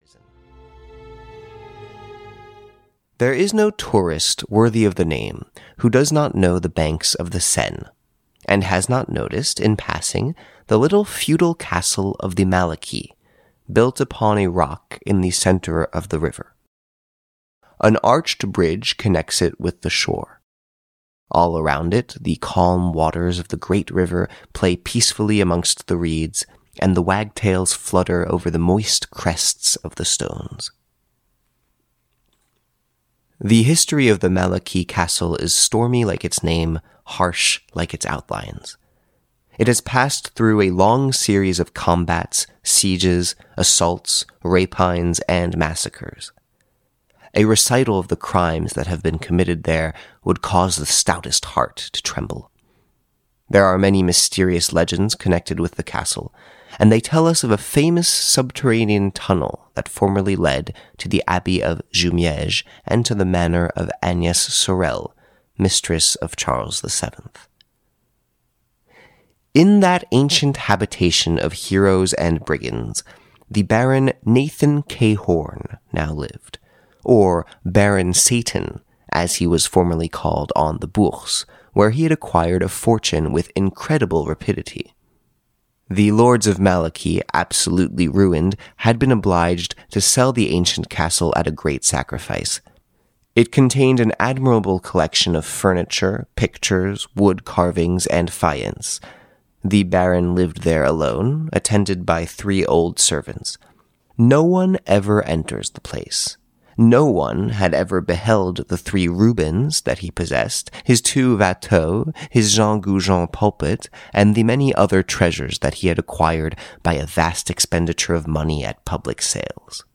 Audio knihaArsène Lupin, Gentleman–Thief: 10 Stories (EN)
Ukázka z knihy